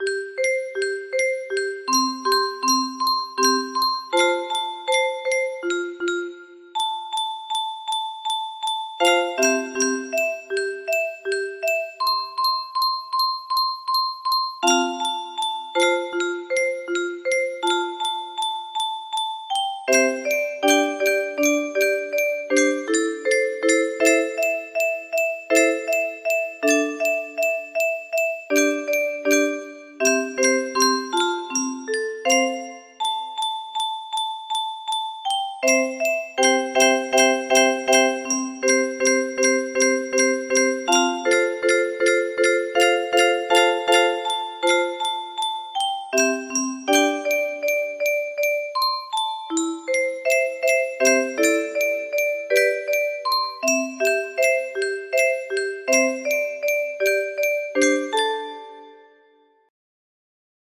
Imported from MIDI from imported midi file (7).mid